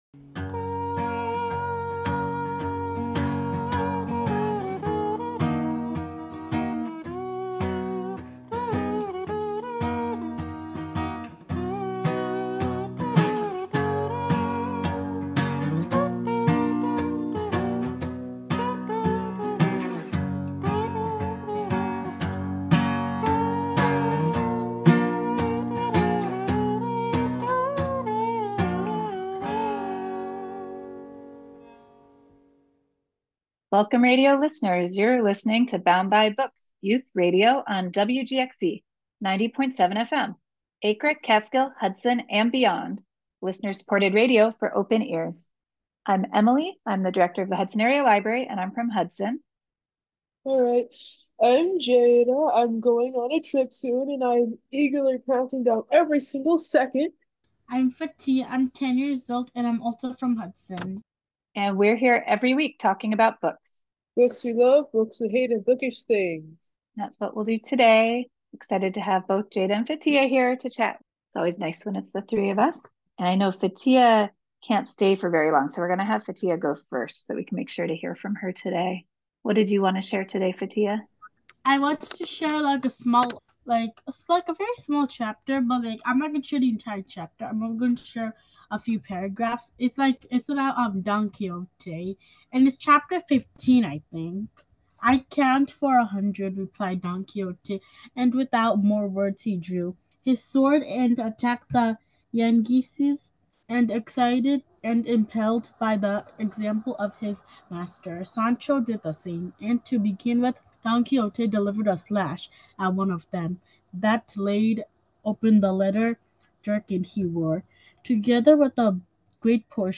The Bound By Books crew discusses books they love, books they're currently reading, and other teen media and literary topics.